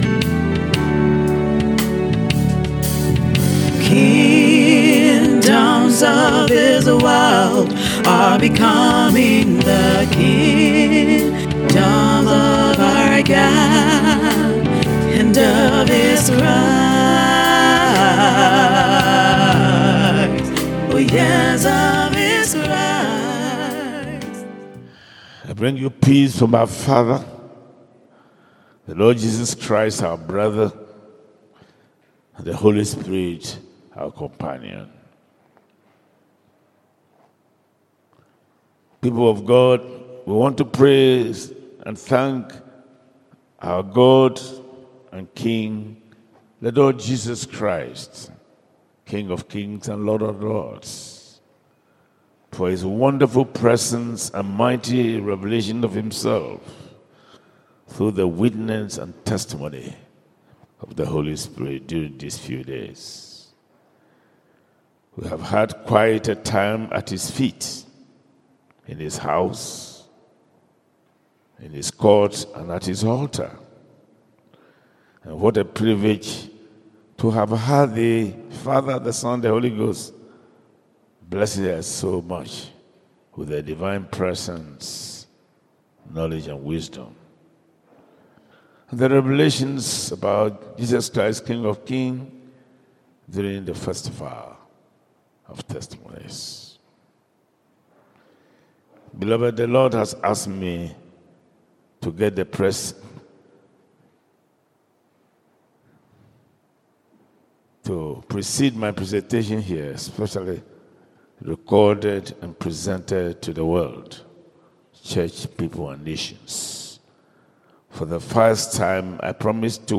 Series: Audio Sermon